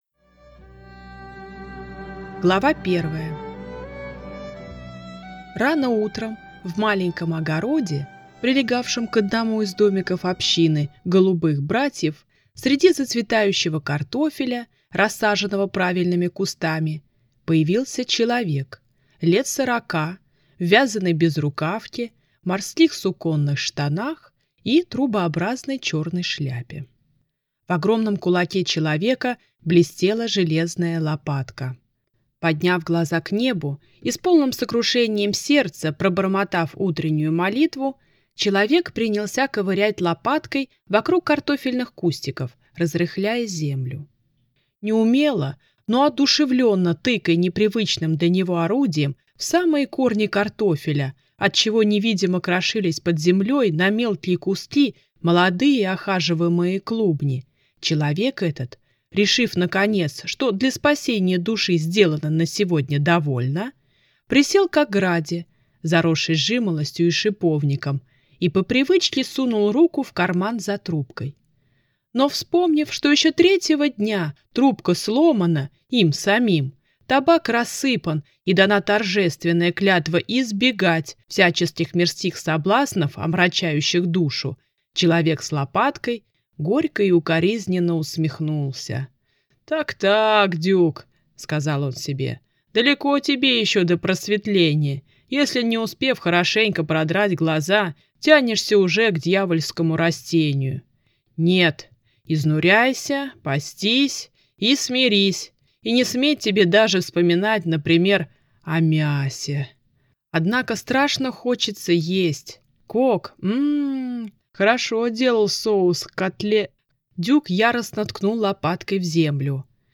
Аудиокнига Капитан Дюк | Библиотека аудиокниг